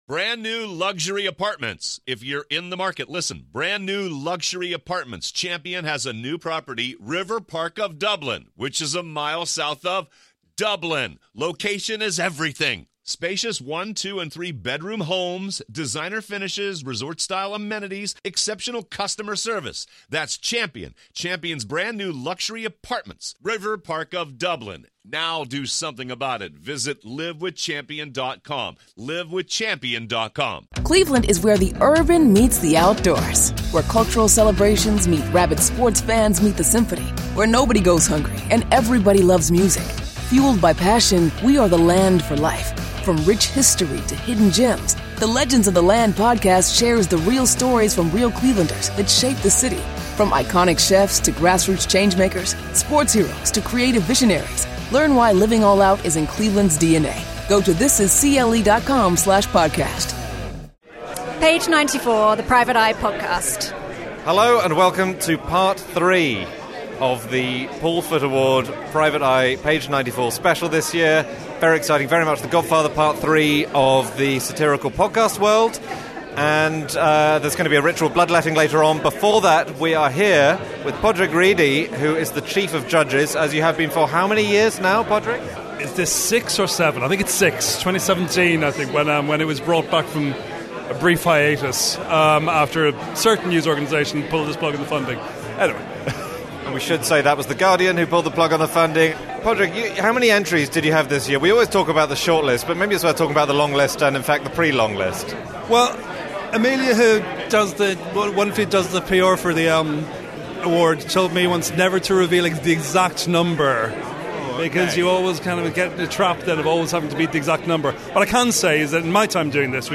Find out which of this year’s six entries won the grand prize, in a mini-episode recorded live at the Paul Foot Award in London.